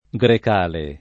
grecale [ g rek # le ]